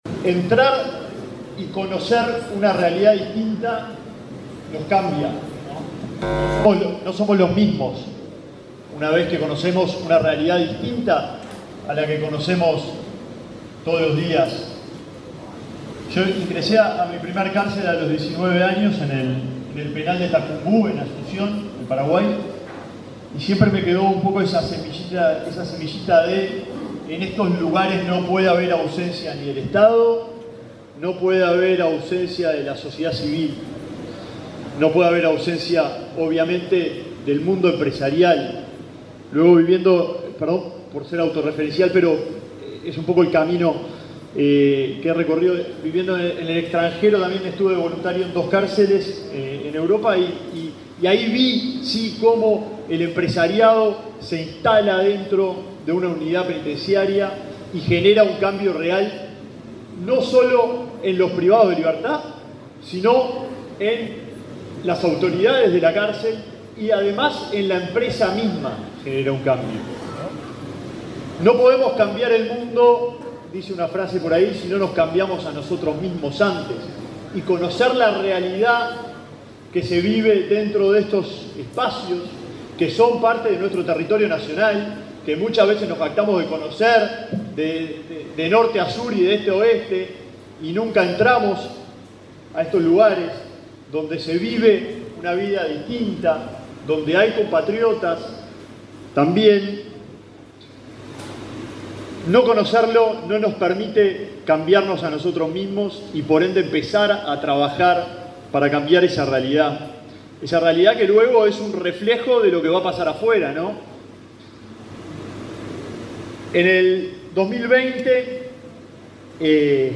Palabras de autoridades en acto en Santiago Vázquez
Palabras de autoridades en acto en Santiago Vázquez 21/11/2024 Compartir Facebook X Copiar enlace WhatsApp LinkedIn El ministro de Desarrollo Social, Alejandro Sciarra, y su par del Interior, Nicolás Martinelli, participaron, este jueves 21 en el polo de Santiago Vázquez, en el acto de firma de un convenio con representantes de la Asociación de Dirigentes de Empresas, para la inserción laboral de personas privadas de libertad.